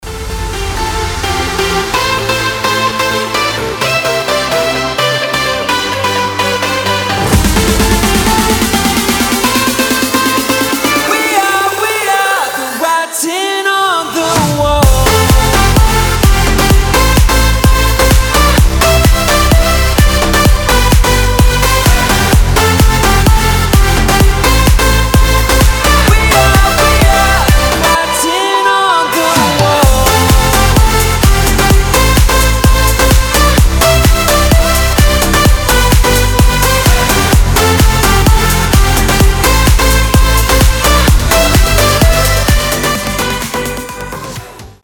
• Качество: 320, Stereo
громкие
Electronic
EDM
нарастающие
энергичные
Big Room
Стиль: progressive house